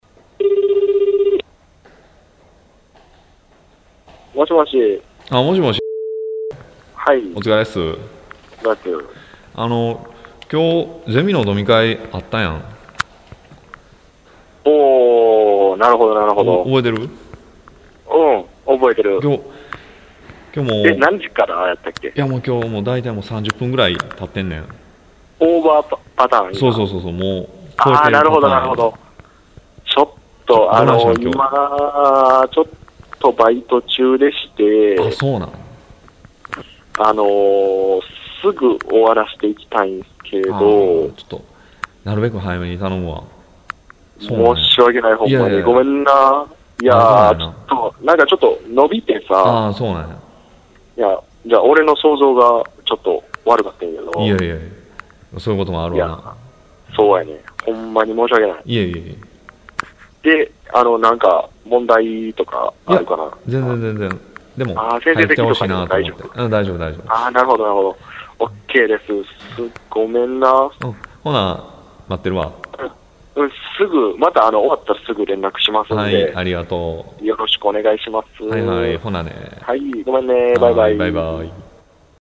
方言ロールプレイ会話データベース ペア入れ替え式ロールプレイ会話＜関西＞
関西若年層男性ペア５